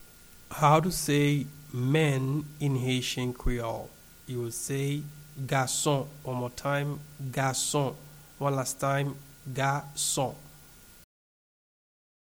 Man-in-Haitian-Creole-Gason-pronunciation.mp3